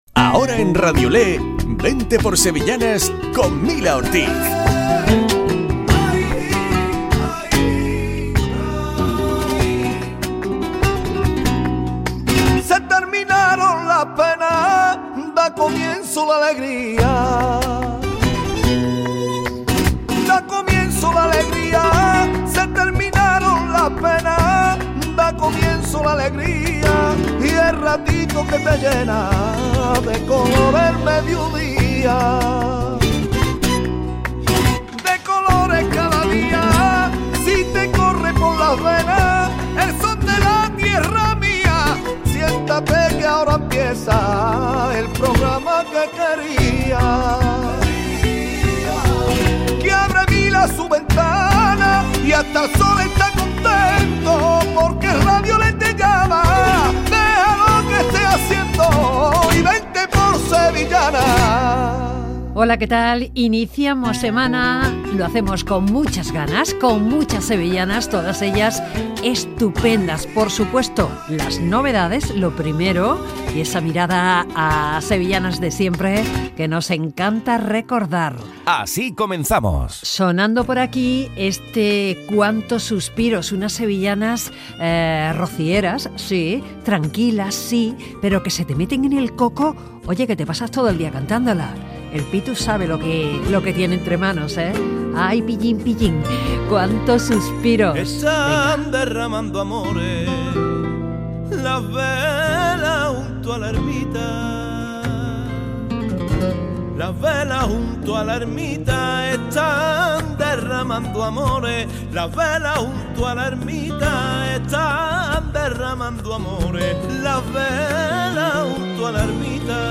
Programa dedicado a las sevillanas .